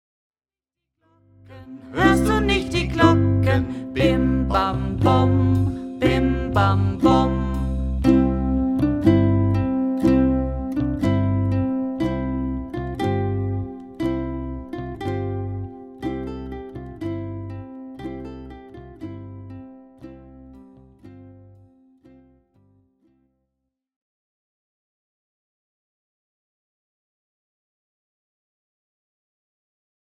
für Ukulele, leicht, 106 Seiten, mit online audio, Ringbuch